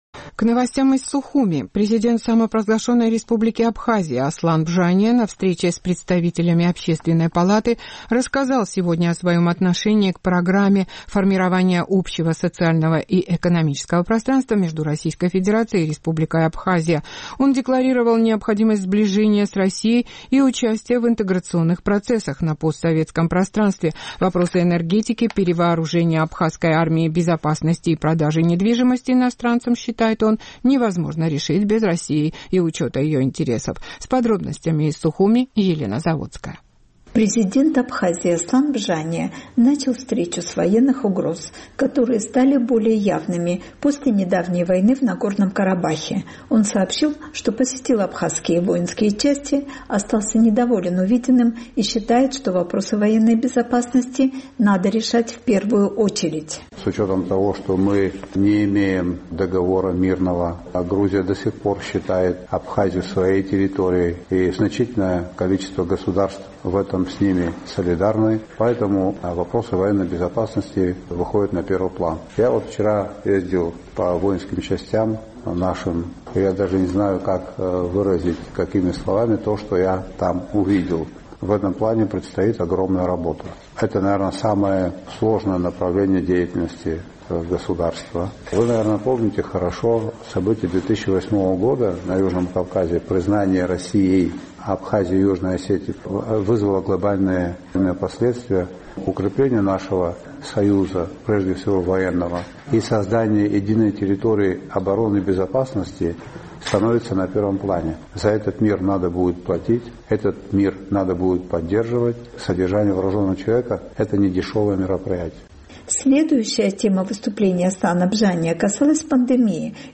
Президент Аслан Бжания на встрече с представителями Общественной палаты рассказал о своем отношении к Программе формирования общего социального и экономического пространства между Российской Федерацией и Республикой Абхазия. Он декларировал необходимость сближения с Российской Федерацией и участия в интеграционных процессах на постсоветском пространстве.